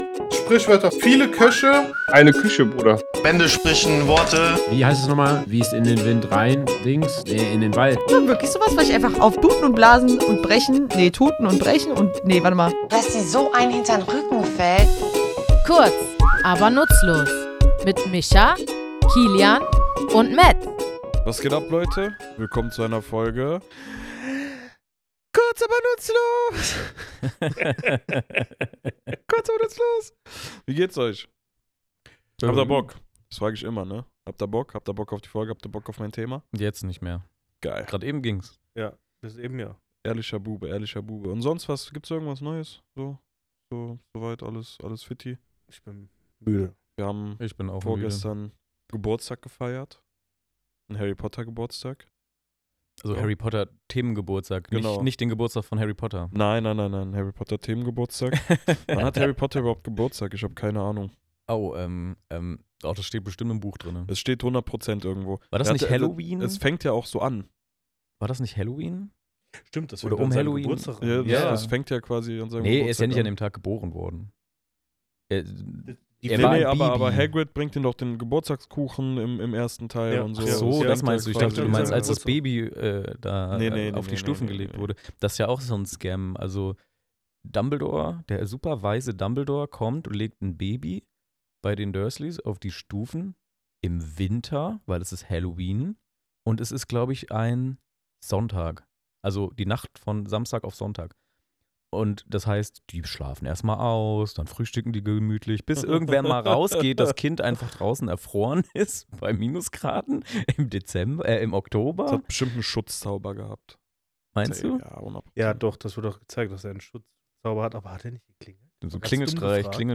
Woher kommt dieser Ausdruck, und welche Bedeutung steckt dahinter? Wir, drei tätowierende Sprachfans, gehen in unserem Tattoostudio den Ursprüngen dieser Redensart auf den Grund.